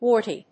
音節wart・y 発音記号・読み方
/wˈɔɚṭi(米国英語), wˈɔːṭi(英国英語)/